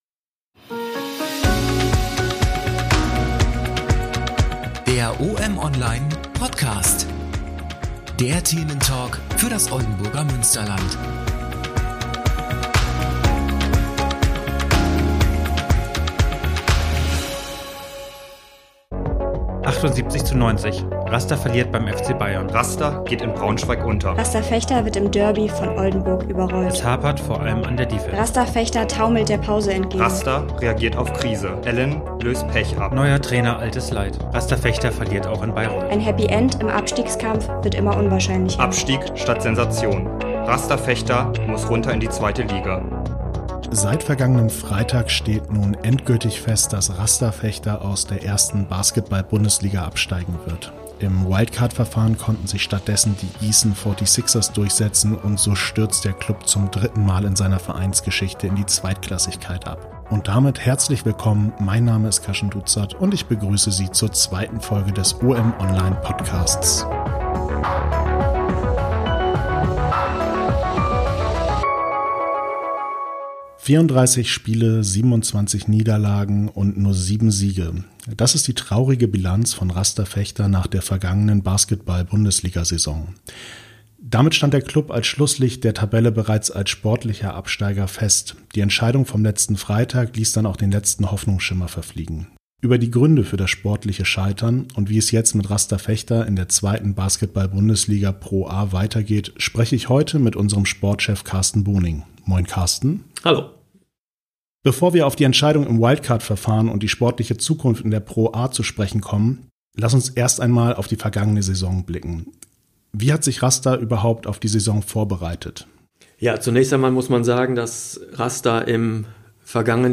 Der Thementalk für das Oldenburger Münsterland: In der 2.